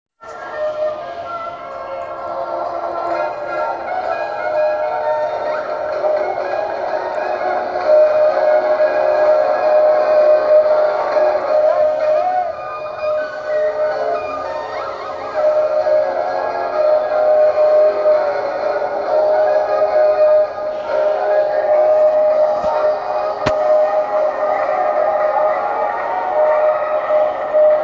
* (Before the age of cell phones I brought my little micro-cassette recorder and made a crude but cool recording of the circus from inside the band I played with during the next to last show on Saturday.
I remember it was really exciting to hear it next to me live.)
circus-soaring-trumpet.wav